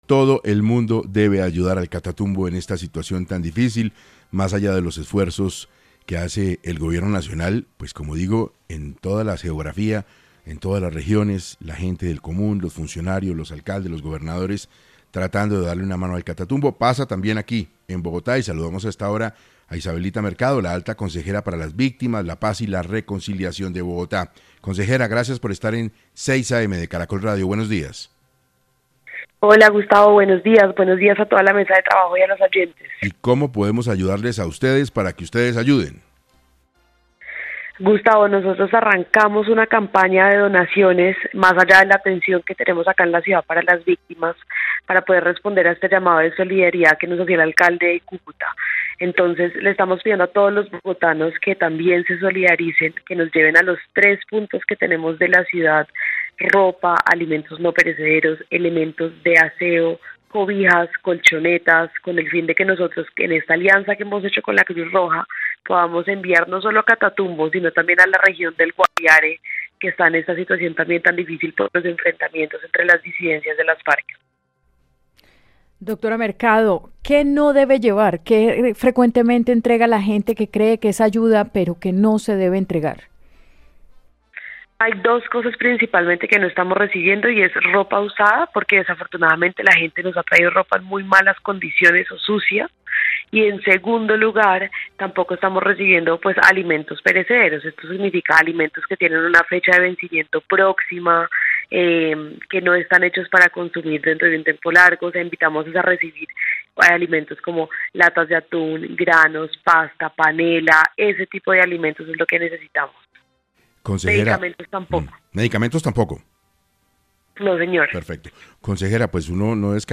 Isabelita Mercado, Alta Consejera para Víctimas, Paz y Reconciliación explicó en 6AM cuáles son los alimentos y utensilios que puede donar para las víctimas de los enfrentamientos entre disidencias y el ELN.